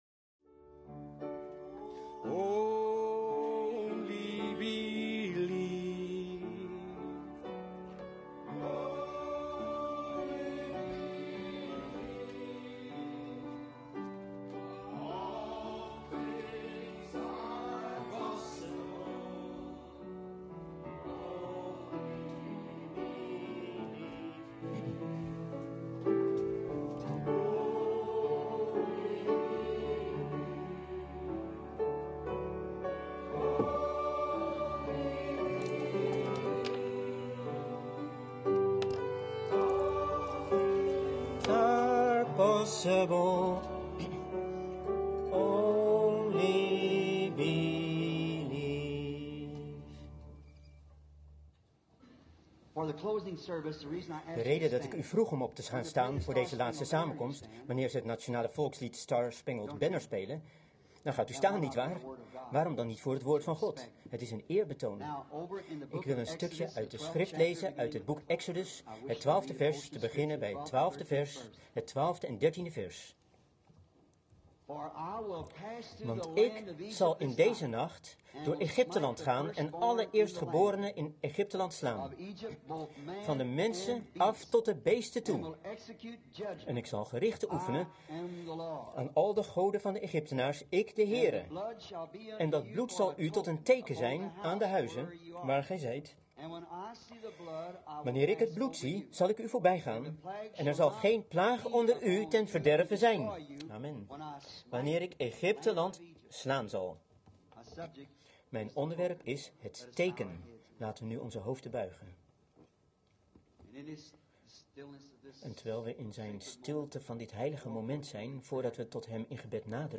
De vertaalde prediking "The token" door William Marrion Branham gehouden in Soul's Harbor temple, Dallas, Texas, USA, 's middags op zondag 08 maart 1964